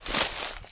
High Quality Footsteps
STEPS Bush, Walk 06.wav